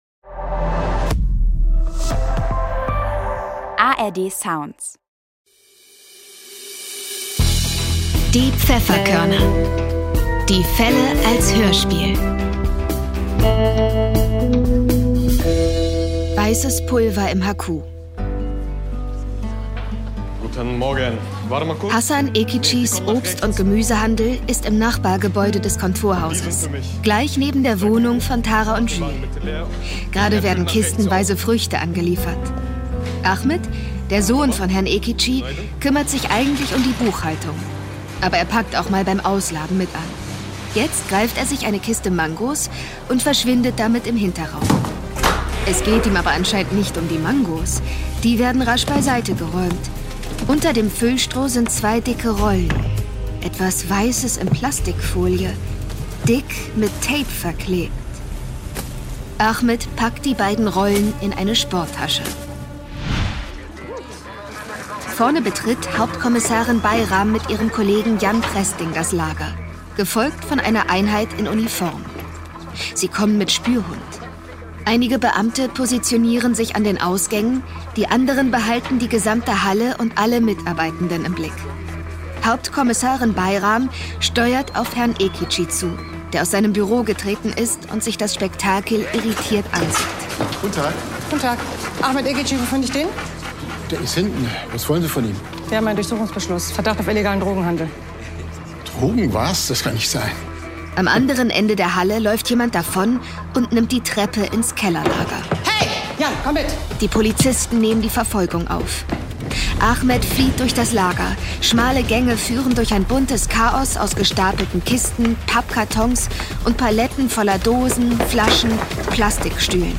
Weißes Pulver im HQ (21/21) ~ Die Pfefferkörner - Die Fälle als Hörspiel Podcast